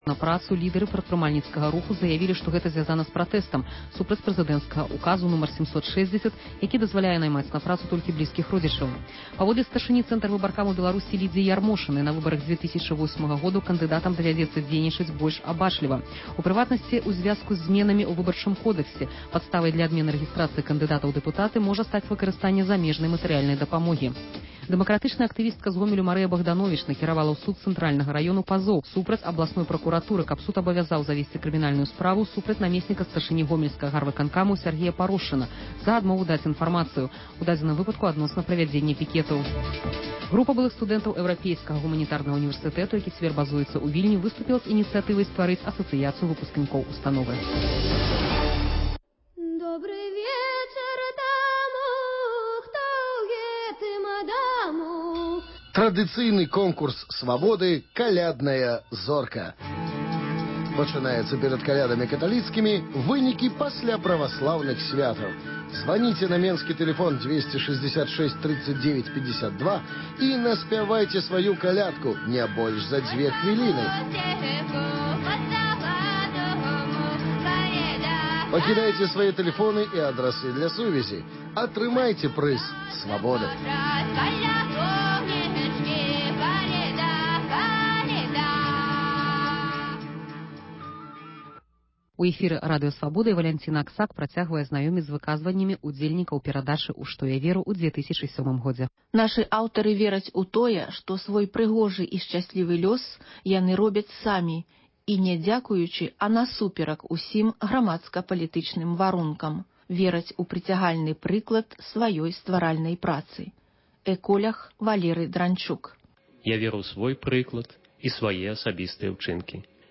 Званкі на “Свабоду”: народ пра Лукашэнку і чыноўнікаў.